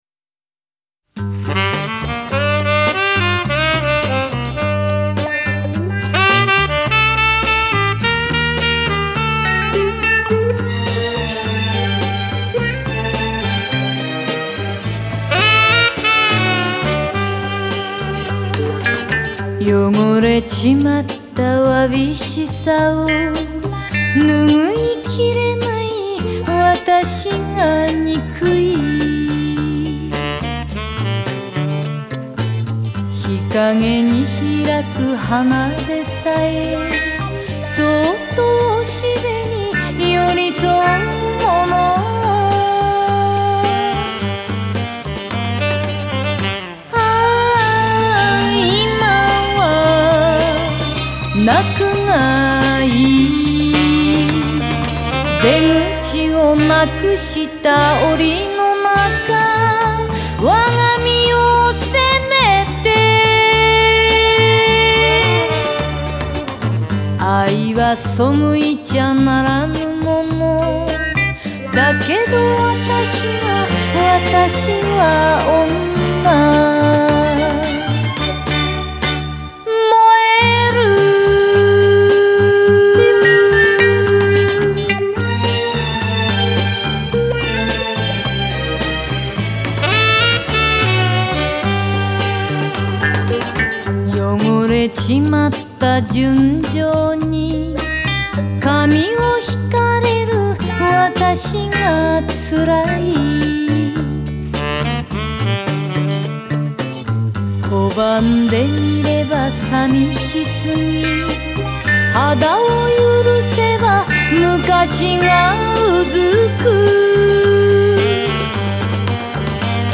Жанр: Enka / J-pop